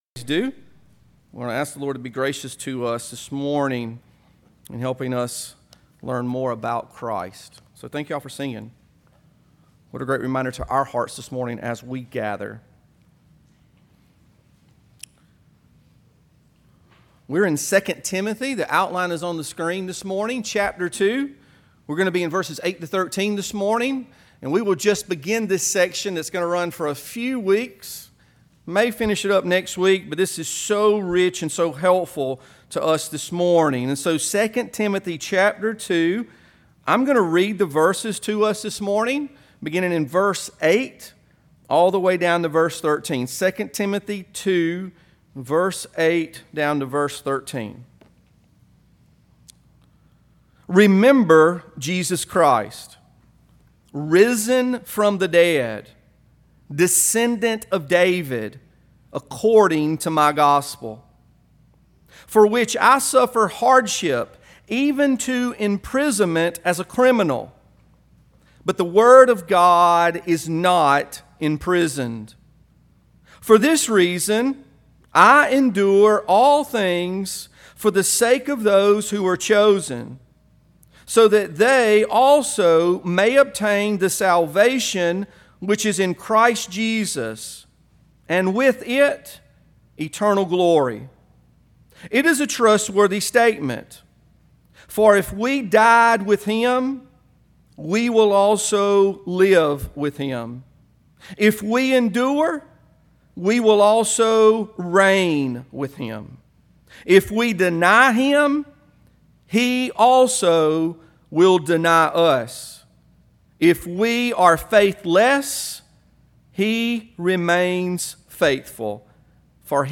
We feature expository preaching, elder rule church polity, reformed soteriology, dispensational eschatology, and a commitment t